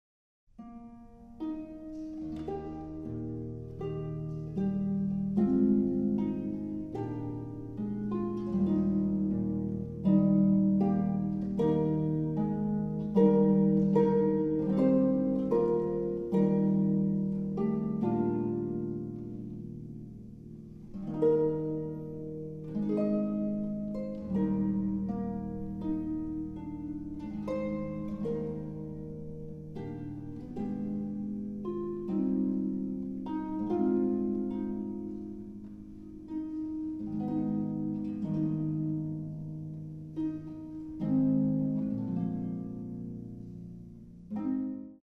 Violin
Viola
Violincello
Harp
Recording Location 덴덴홀 에로라(일본 사이타마현 마츠부시마치)